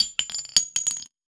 weapon_ammo_drop_22.wav